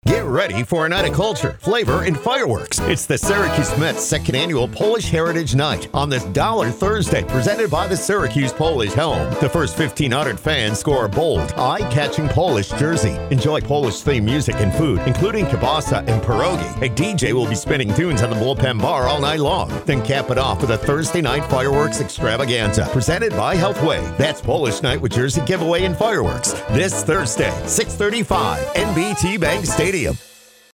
Middle Aged
Senior